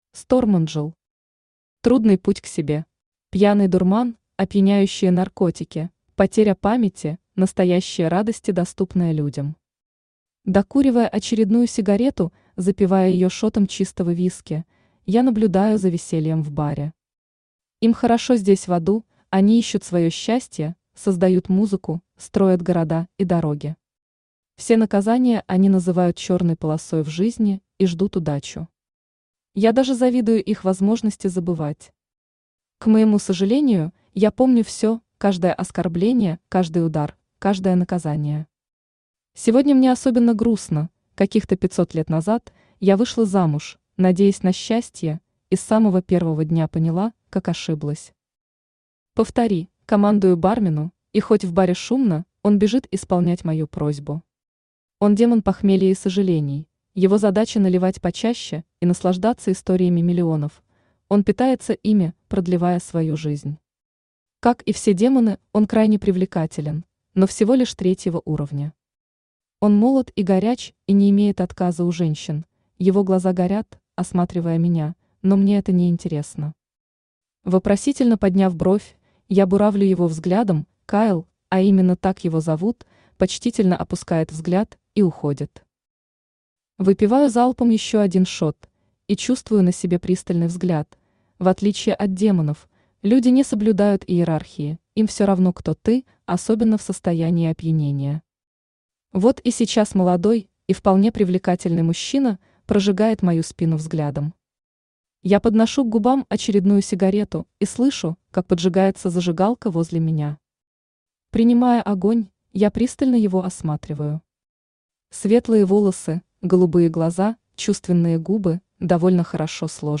Аудиокнига Трудный путь к себе | Библиотека аудиокниг
Читает аудиокнигу Авточтец ЛитРес.